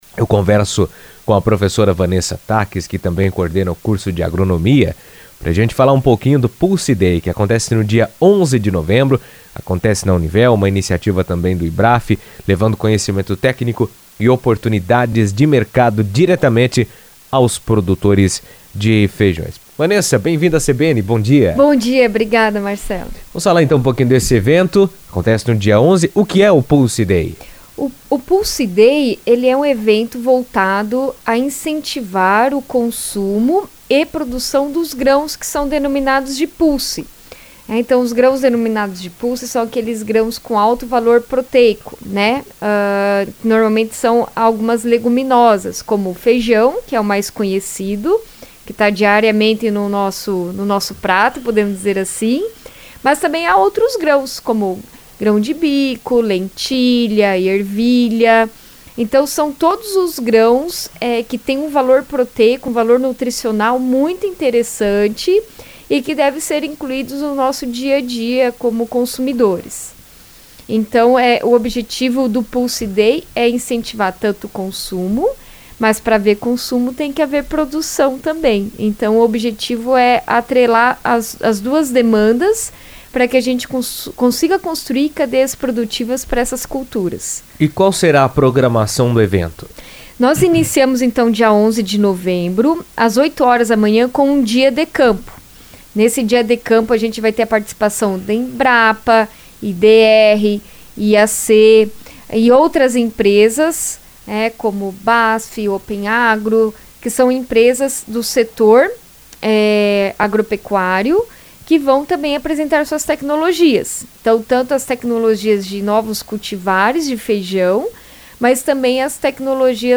O Pulse Day Univel – Feijões vai reunir produtores, pesquisadores e estudantes para debater inovações e oportunidades no setor, com foco em tecnologia e sustentabilidade na produção. Em entrevista à CBN